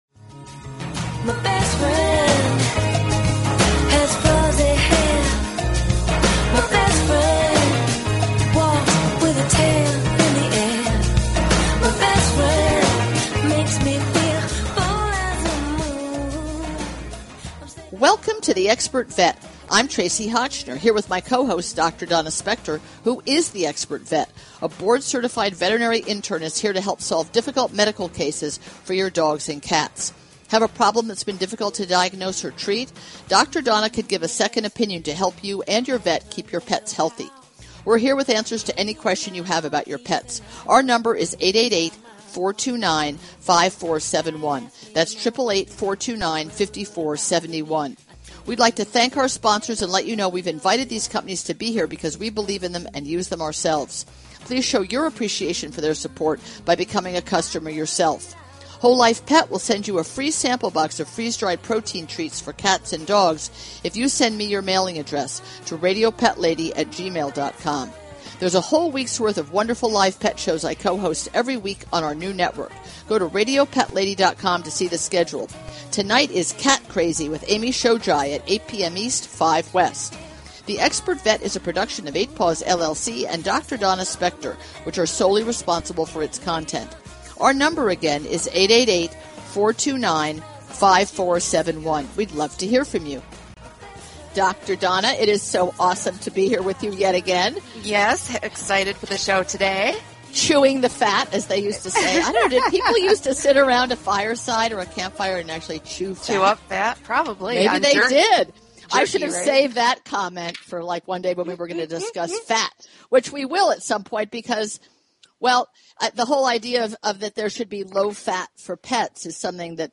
Talk Show Episode, Audio Podcast, The_Expert_Vet and Courtesy of BBS Radio on , show guests , about , categorized as